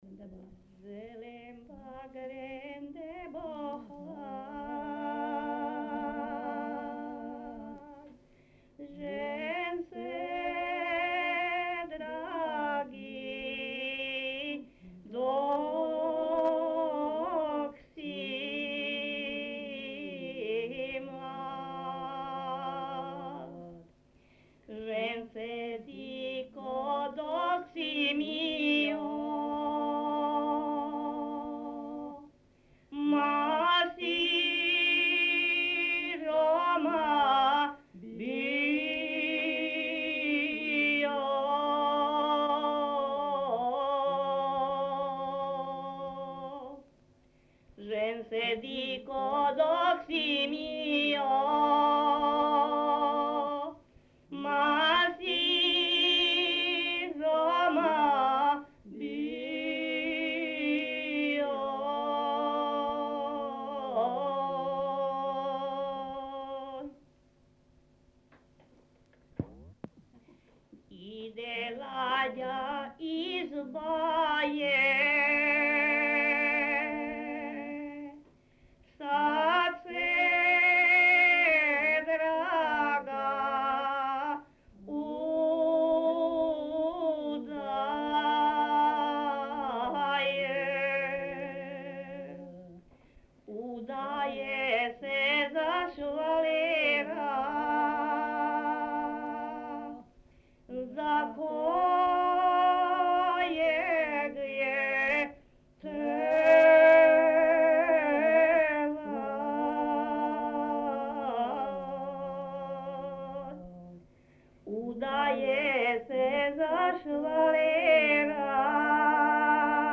Dialect: D
Theme: Refrains, bećarci, shortsongs, potrkušice, šalajke, humorous songs, counting songs, songs from the tavern, songs with the dance
Locality: Magyarcsanád/Čanad
Comment: A slow variant of šalajka, sung to the bass.